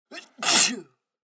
دانلود آهنگ عطسه مرد 1 از افکت صوتی انسان و موجودات زنده
دانلود صدای عطسه مرد 1 از ساعد نیوز با لینک مستقیم و کیفیت بالا
جلوه های صوتی